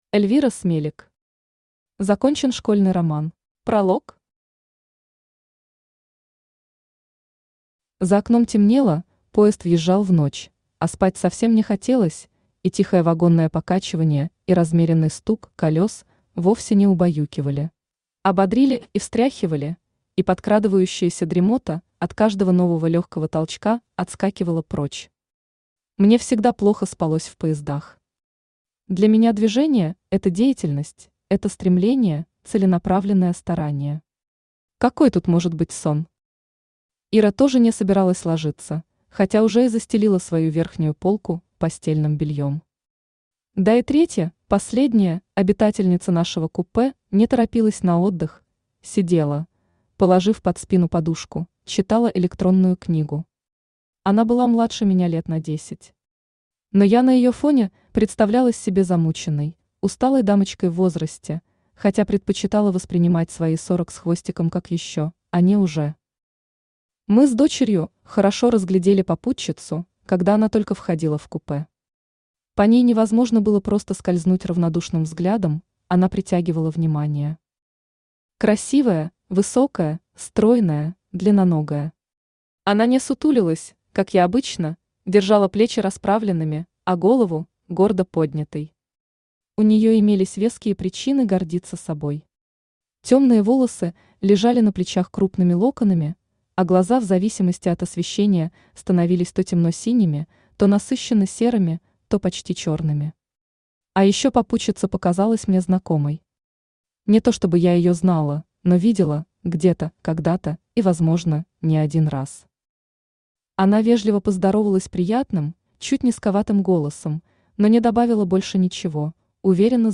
Аудиокнига Закончен школьный роман | Библиотека аудиокниг
Aудиокнига Закончен школьный роман Автор Эльвира Смелик Читает аудиокнигу Авточтец ЛитРес.